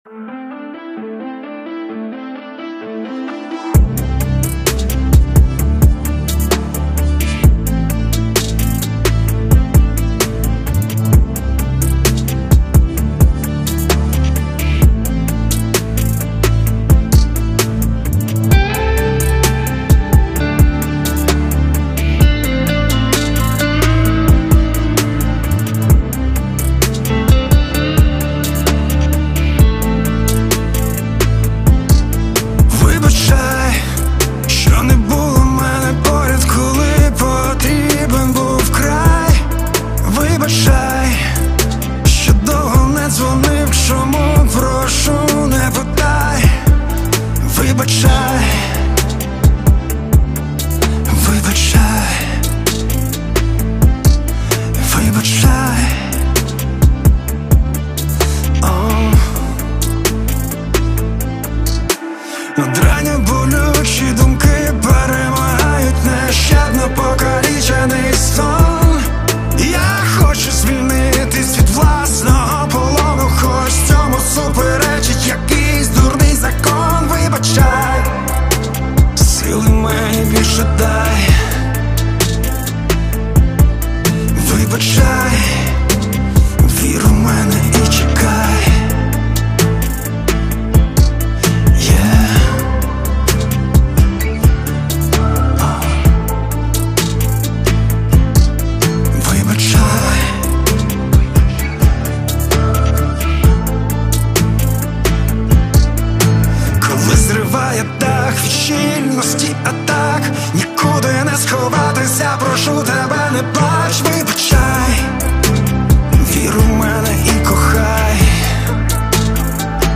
• Жанр:RnB